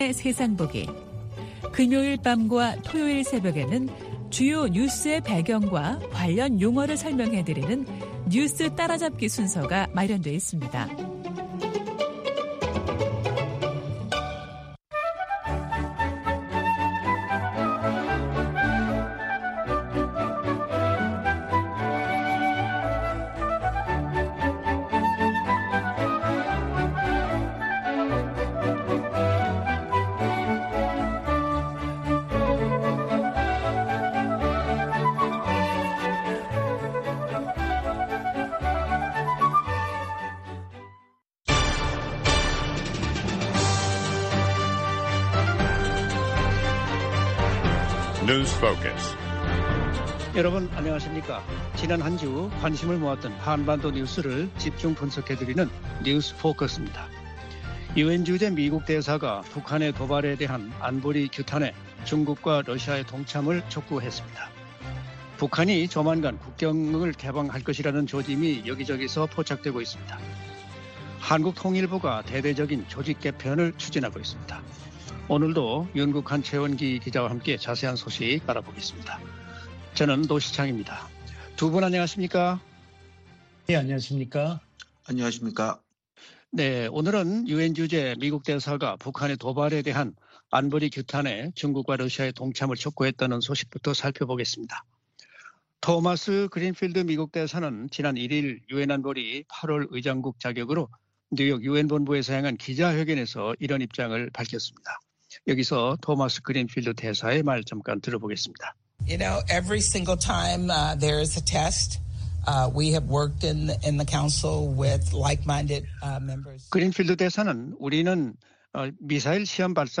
VOA 한국어 방송의 월요일 오전 프로그램 2부입니다. 한반도 시간 오전 5:00 부터 6:00 까지 방송됩니다.